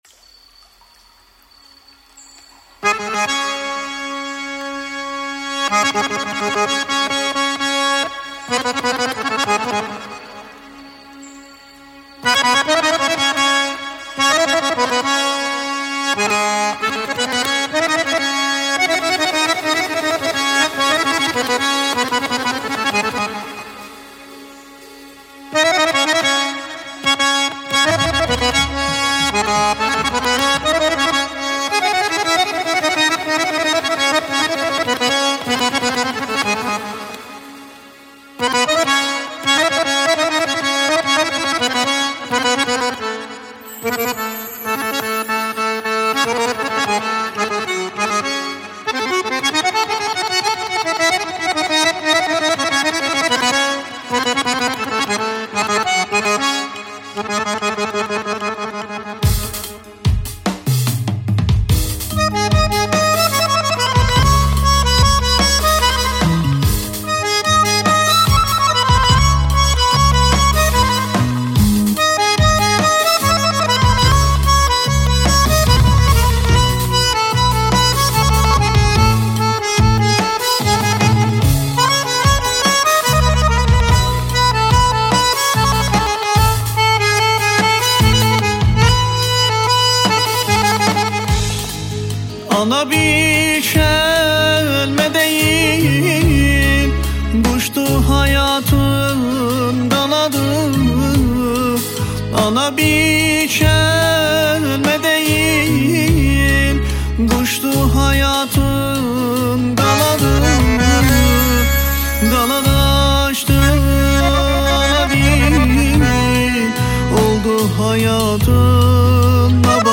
آهنگ ترکی
به به عالی چه اهنگی احساسی خوبی به مقام زیبای مادر